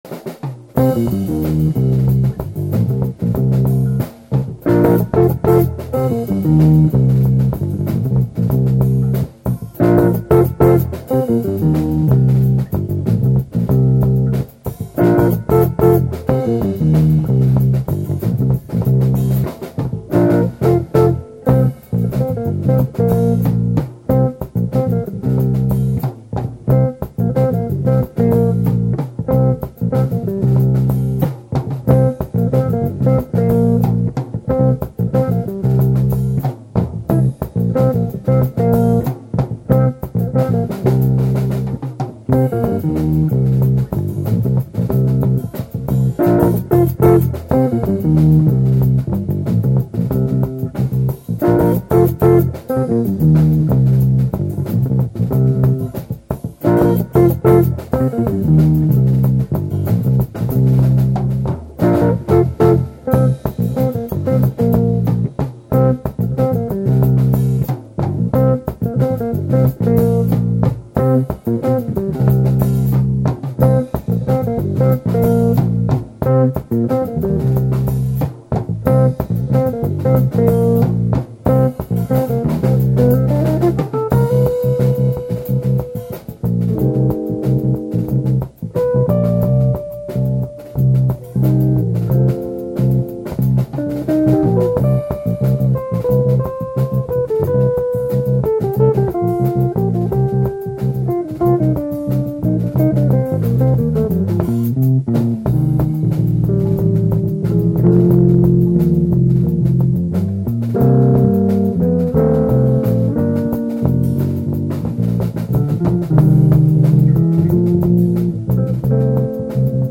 bass guitar
drums
sax
In 1998, the band added jazz influences to their music.